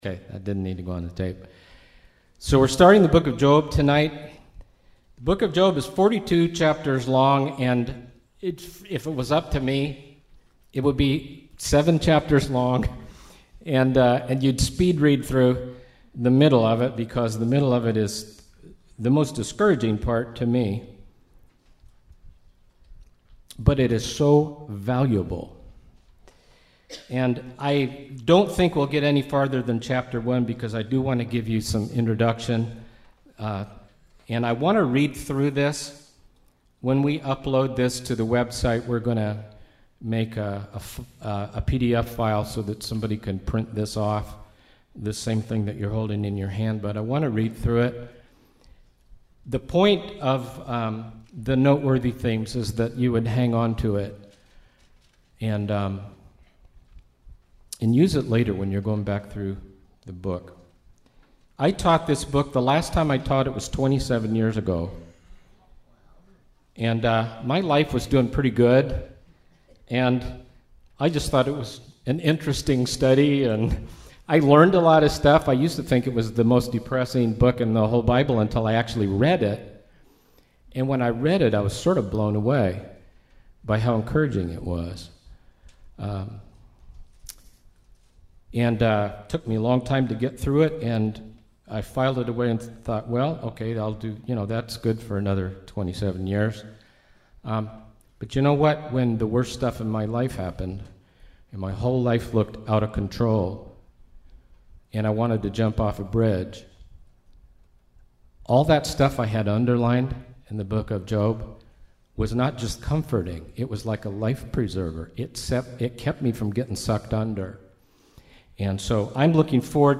Line by line indepth study from the original texts. Midcoast Country Chapel Wiscasset Maine